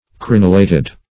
Crenulate \Cren"u*late\ (kr?n"?-l?t), Crenulated \Cren"u*la`ted\